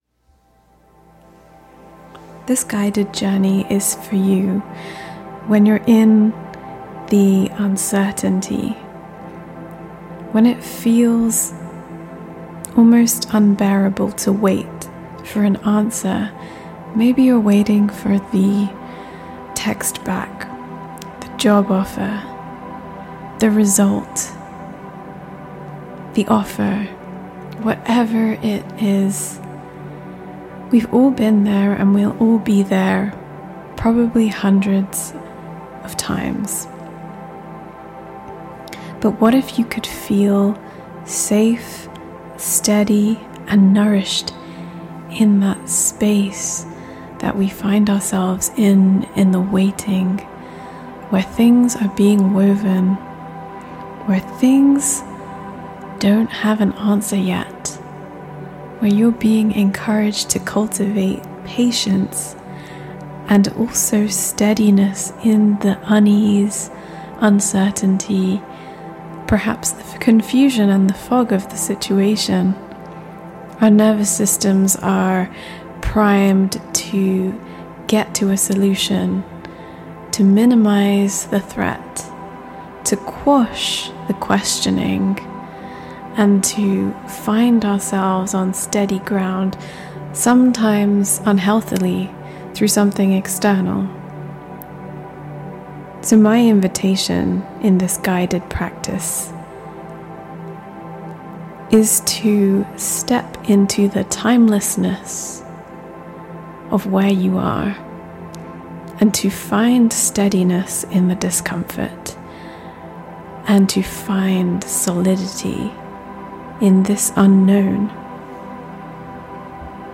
Here is your 20-minute guided journey - a gift from my heart to yours.